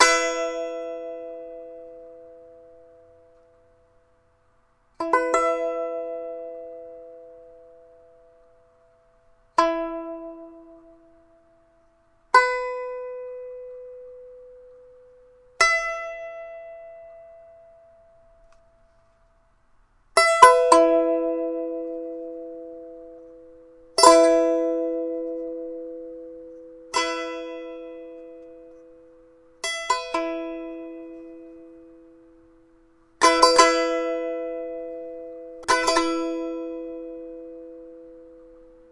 弦乐棒 " 弦乐棒5音阶条琴
描述：与蓝雪球的弦乐和弹拨录音
Tag: 俯仰 乱弹 strumstick 乐器 吉他 strumstick 拔毛 笔记 样品